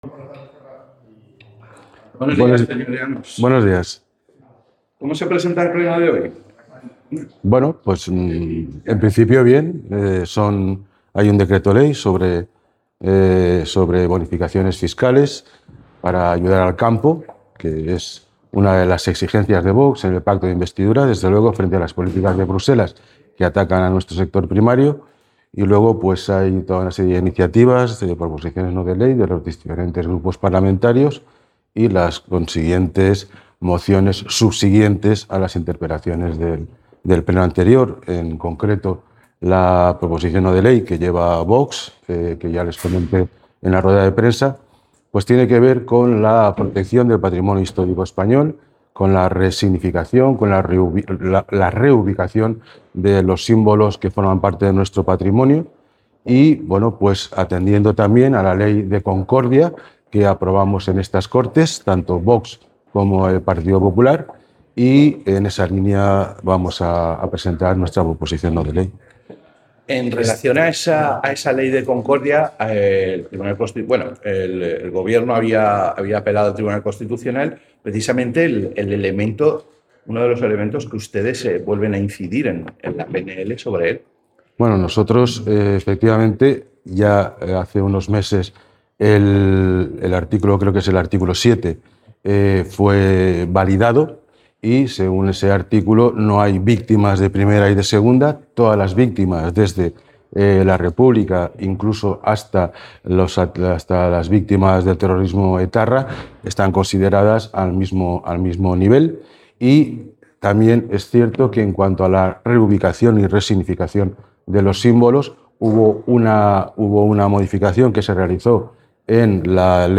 El portavoz de Agricultura de VOX en las Cortes Valencianas, José Muñoz, ha afirmado durante su intervención en el Pleno